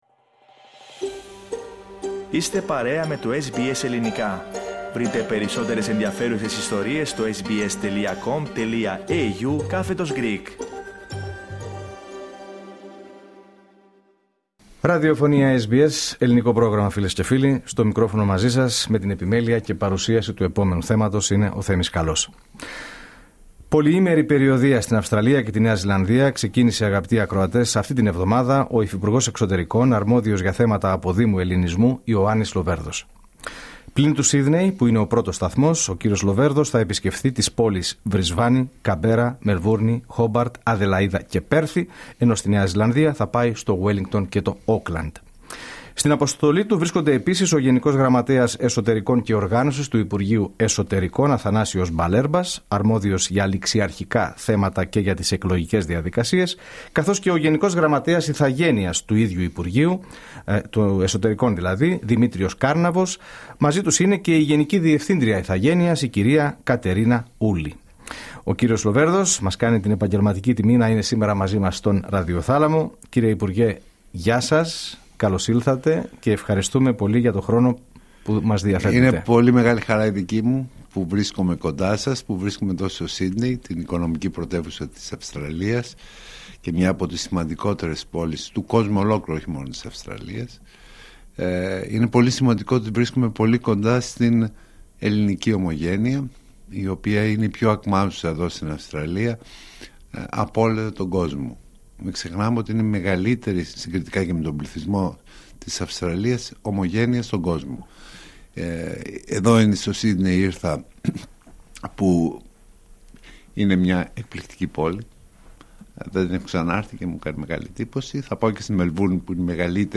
Ο κ. Λοβέρδος στον ραδιοθάλαμο του σταθμού μας, SBS Greek.